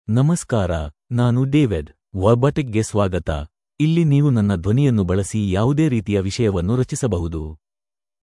DavidMale Kannada AI voice
David is a male AI voice for Kannada (India).
Voice sample
Male
David delivers clear pronunciation with authentic India Kannada intonation, making your content sound professionally produced.